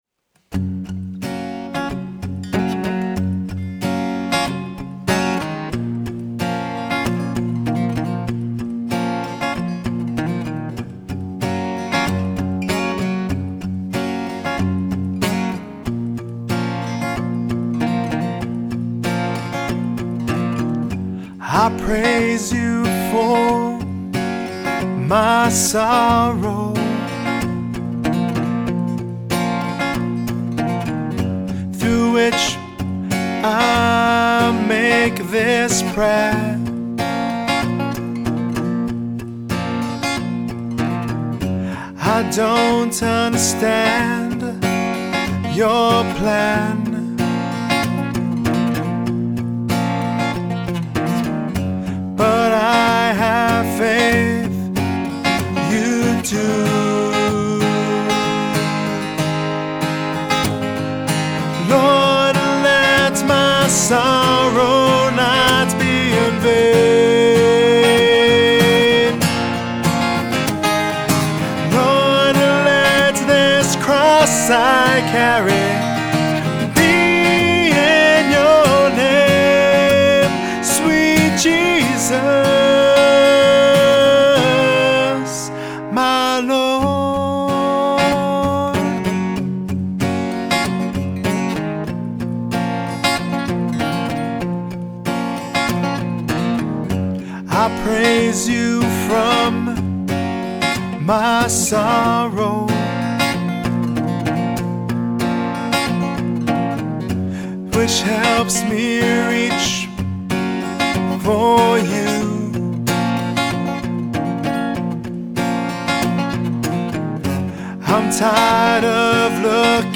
Я хотел, чтобы запись звучала сыро и не отполировано. Я хотел, чтобы это имело живое и аутентичное чувство, и поэтому идея отслеживания всего сразу привлекала меня.
• MXL 603 с (на расстоянии 3 фута, направлено на звуковую скважину гитары) ... Presonus MP20 Jensen / Linear Technologies Операционный канал ... Echo Audiofire 1/4 "вход
Поговорив об этом некоторое время, я включил сжатие и тот факт, что на каждом треке он был там.